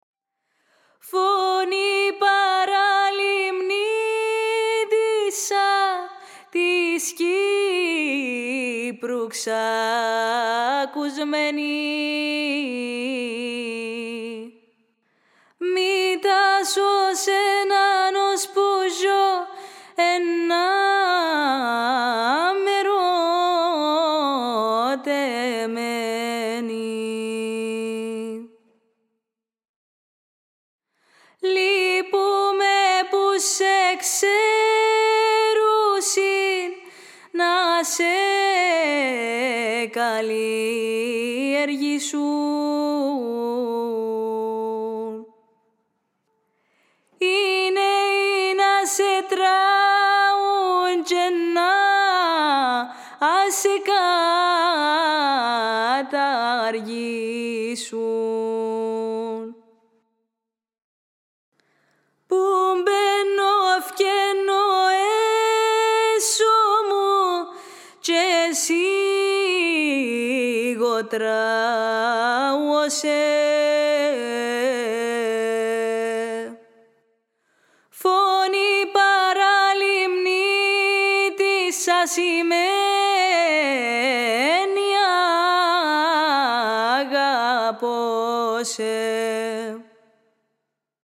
Η κυπριακή παραδοσιακή μουσική
2139_01.ΠΑΡΑΛΙΜΝΙΤΙΣΣΑ ΦΩΝΗ.mp3